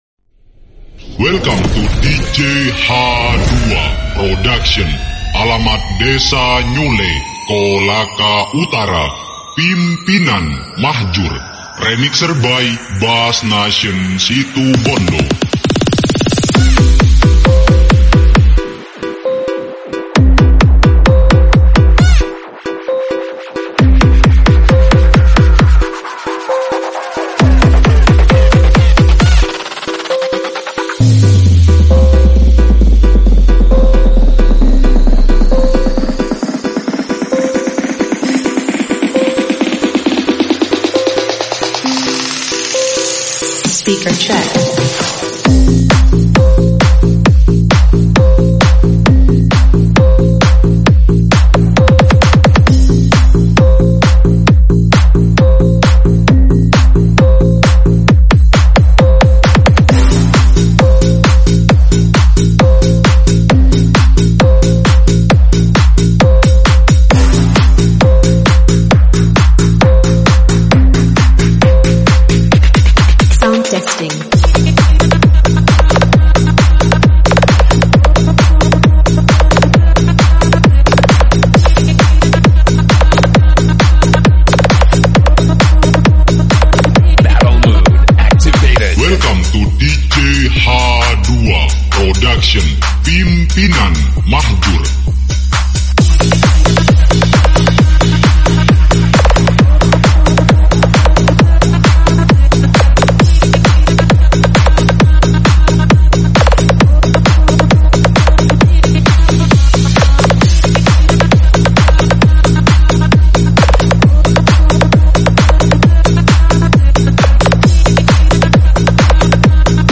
batle nulup bass mantap lur